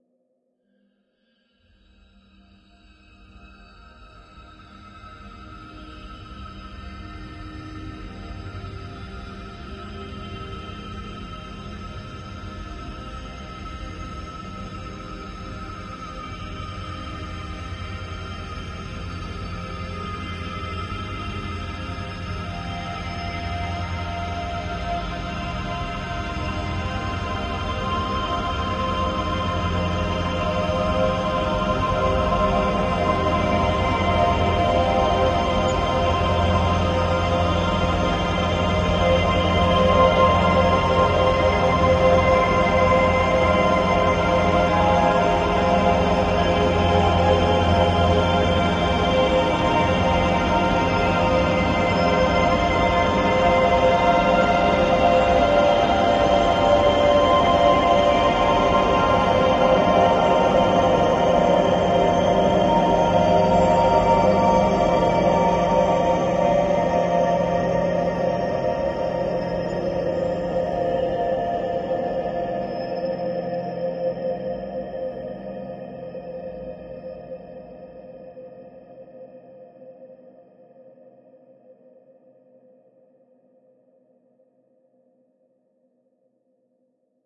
无人机 " 唱歌的碗 ' 超级无人机' III
描述：“G”（“喉咙脉轮”）喜马拉雅唱歌碗的声音被嗡嗡作响，然后将所产生的无人机复制，俯仰和多轨以产生长而微妙的无人机。
标签： 声景 沉思 环境 多重采样 梦幻 无人驾驶飞机 唱歌碗 光滑 松弛 演进
声道立体声